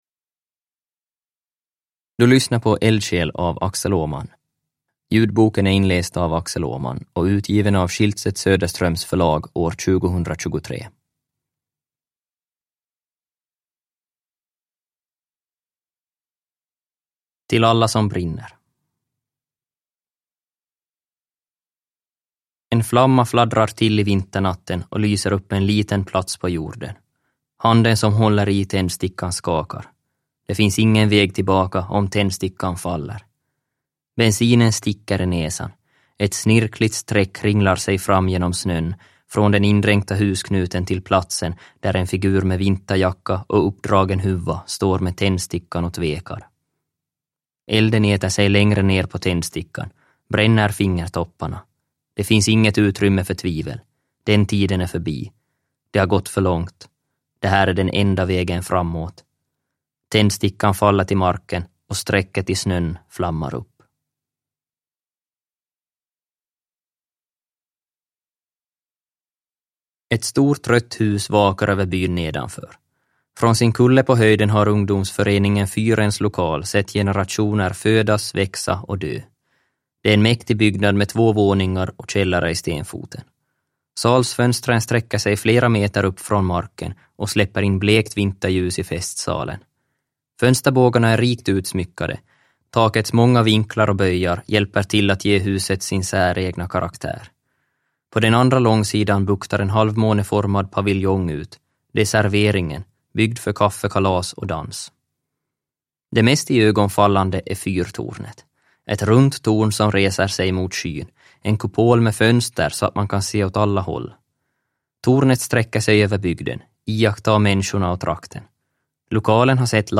Eldsjäl – Ljudbok – Laddas ner